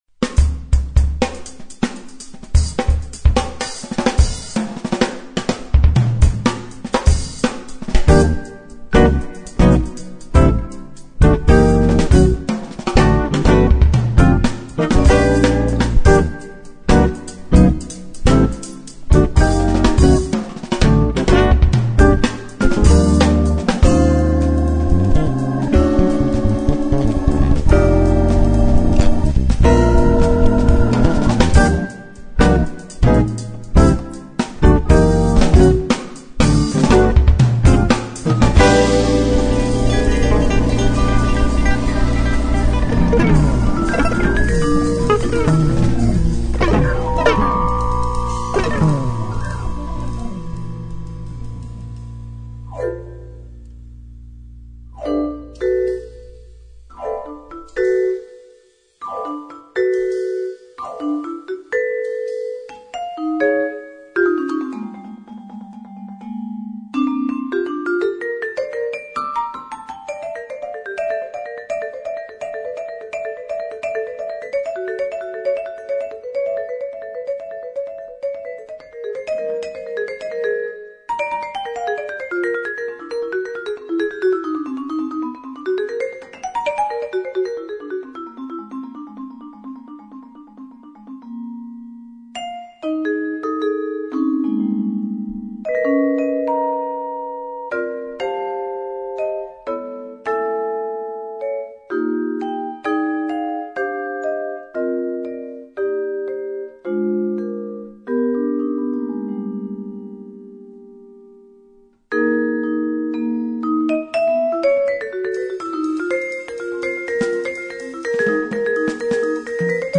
future jazz'in tanımı olacak kadar başarılı